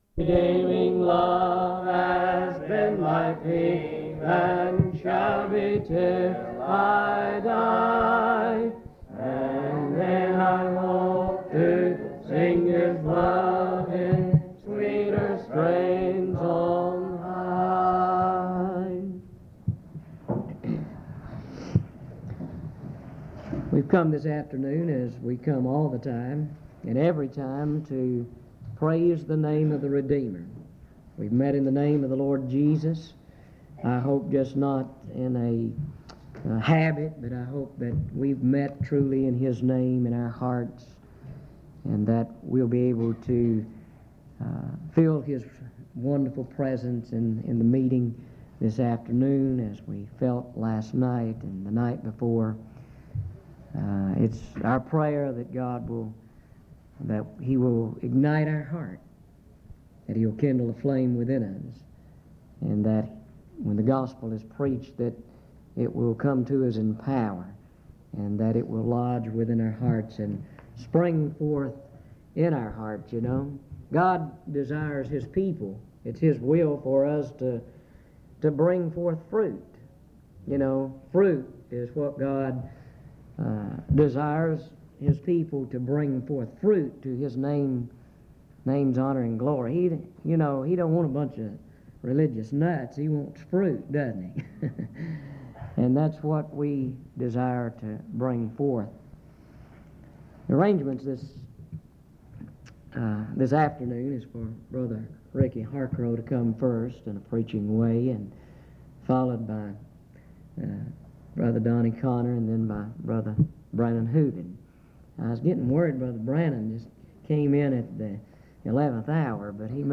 Recording of an afternoon service including sermons by three elders